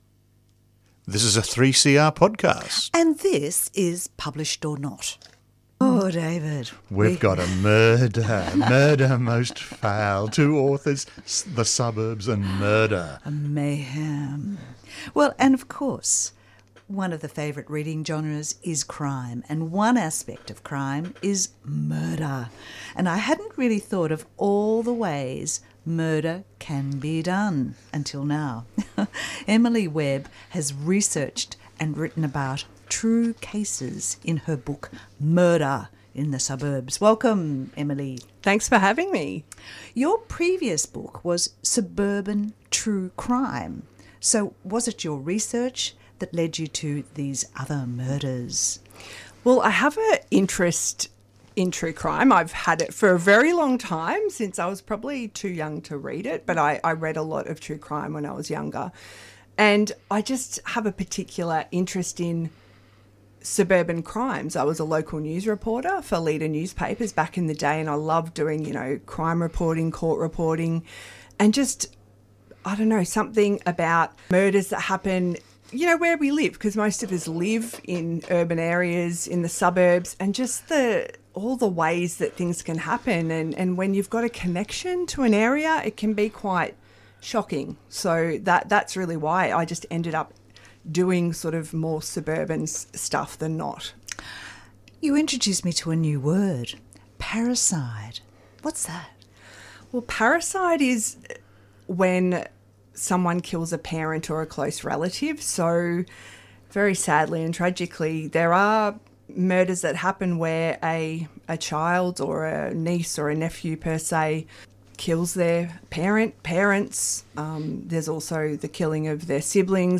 Tweet Published...Or Not Thursday 11:30am to 12:00pm Australian and international authors talk about their books and how they got published or how they self-published.